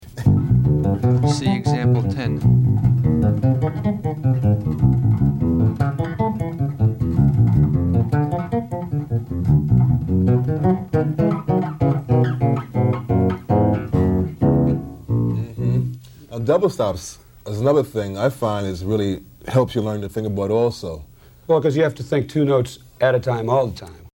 Voicing: Bass Guitar